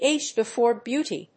アクセントÁge befòre béauty!